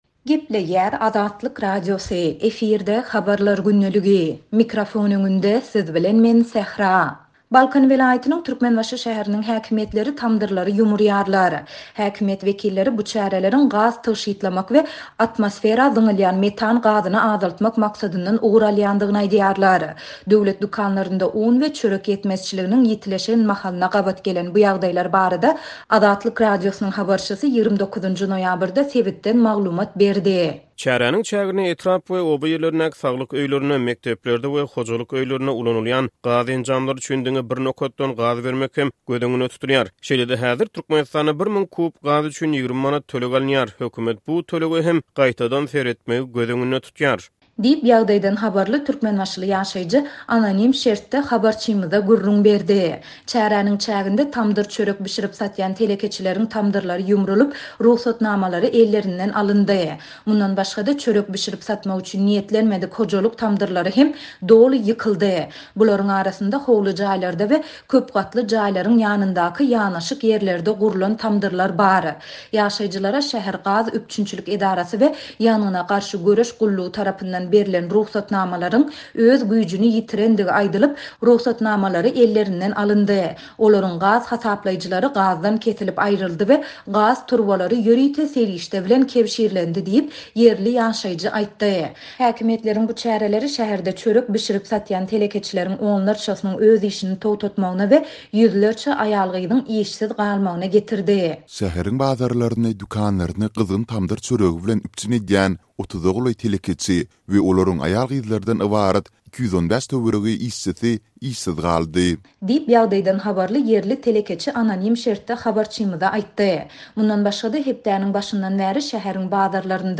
Döwlet dükanlarynda un we çörek ýetmezçiliginiň ýitileşen mahalyna gabat gelen bu ýagdaýlar barada Azatlyk Radiosynyň habarçysy 29-njy noýabrda sebitden maglumat berdi.